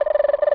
cartoon_electronic_computer_code_05.wav